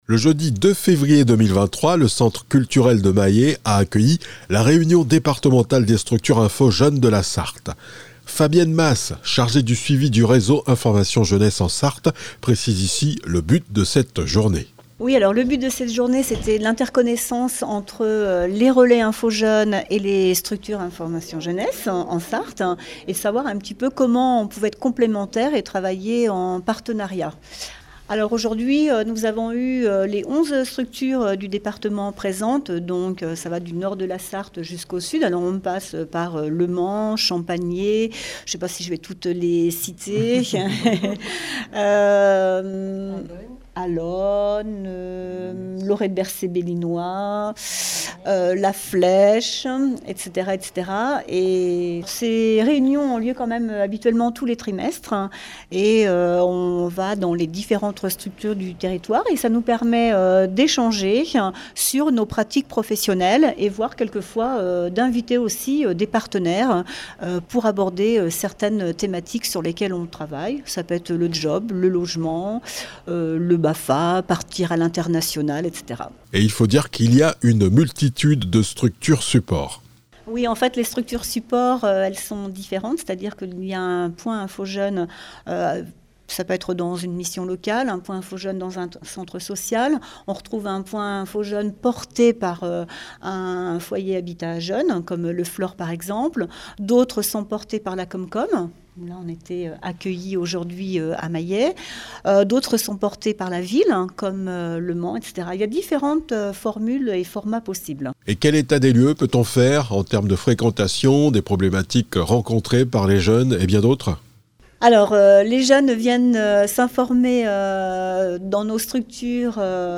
Reportage réunion Réseau Infos Jeunes à Mayet